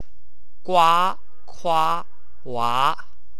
与普通话（ua）对应。